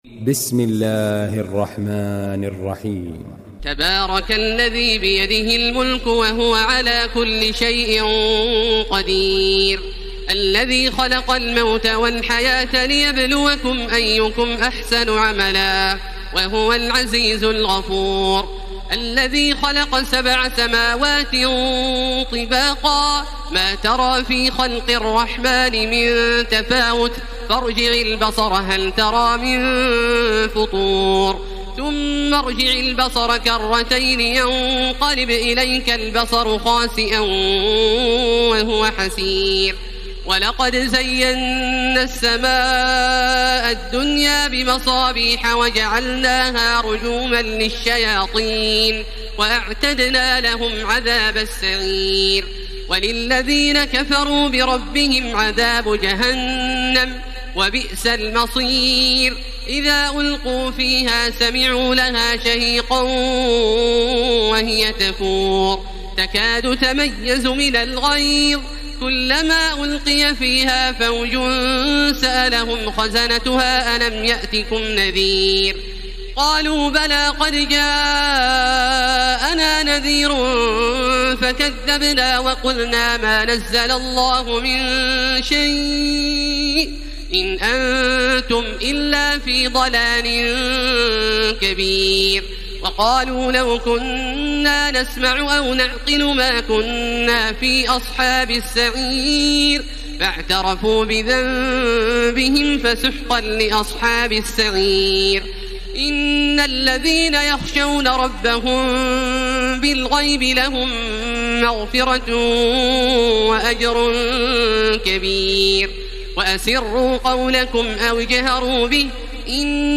تراويح ليلة 28 رمضان 1434هـ من سورة الملك الى نوح Taraweeh 28 st night Ramadan 1434H from Surah Al-Mulk to Nooh > تراويح الحرم المكي عام 1434 🕋 > التراويح - تلاوات الحرمين